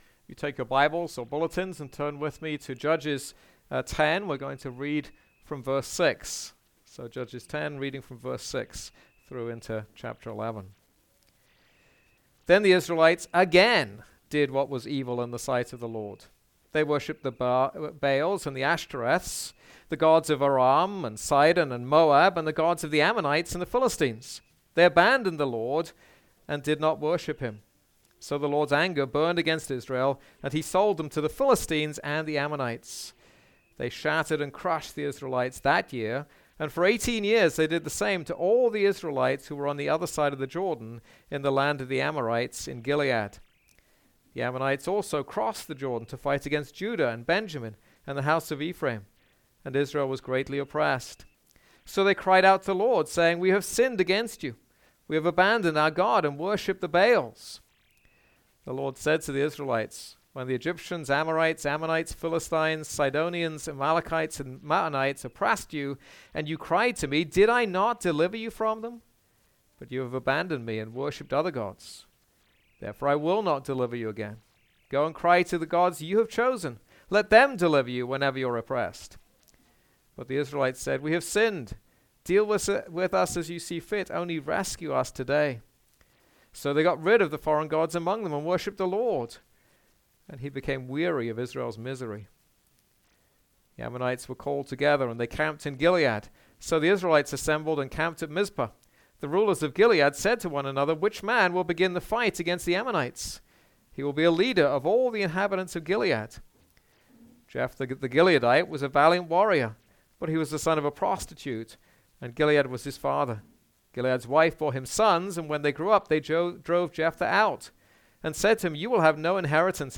This is a sermon on Judges 10:6-11:40.